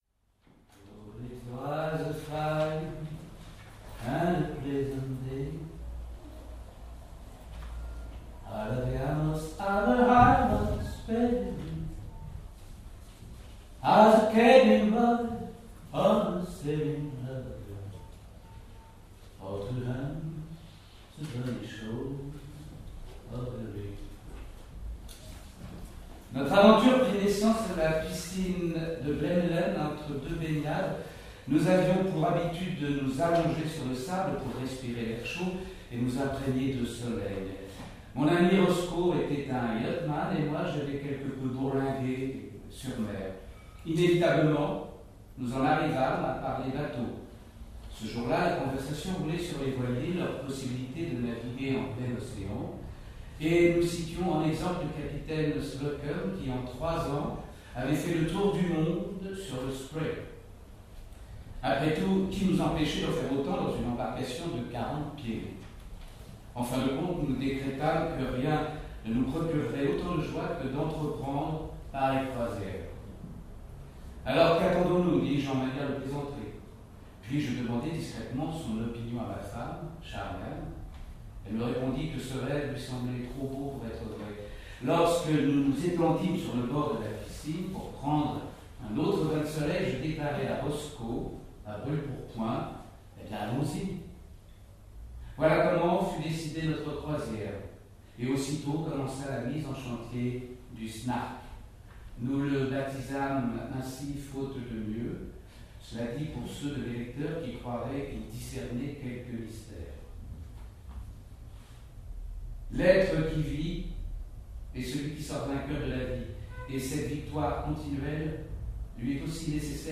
Comme la saison passée, les lectures nées au salon Roger Blin s’invitent dans les Monuments Nationaux, dessinant ainsi une géographie de la littérature.